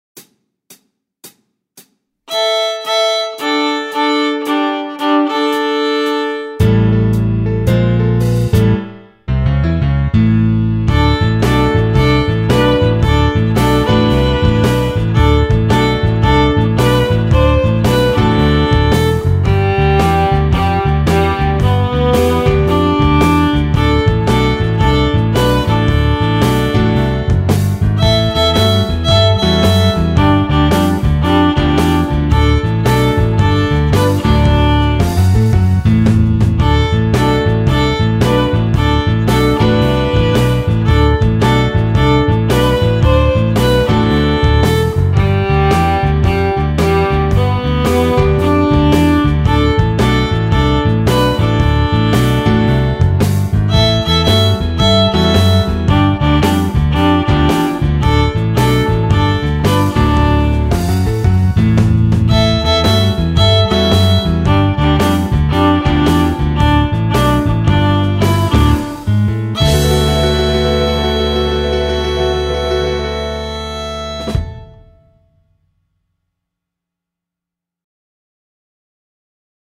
De cada una de ellas hay un audio con el violín tocando y el piano y la batería acompañando y el segundo audio con la segunda voz, el piano y la batería.